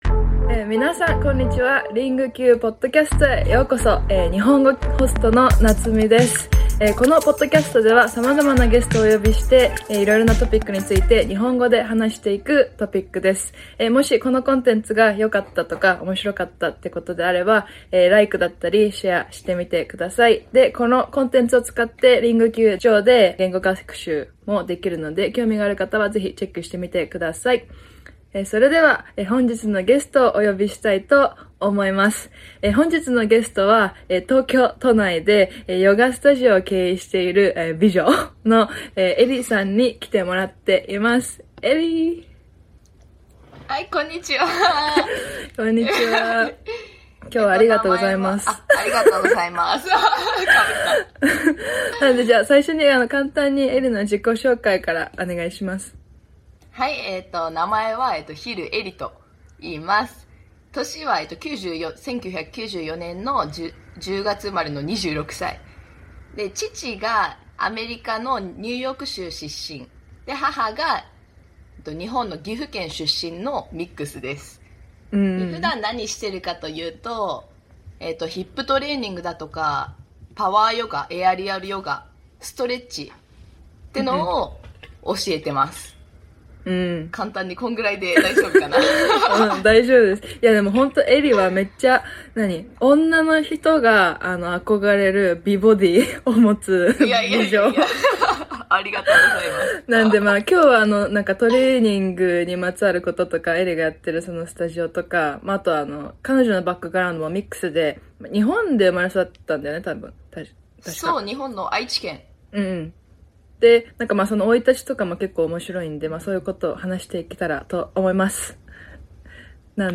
An interview about managing a yoga studio in downtown Tokyo.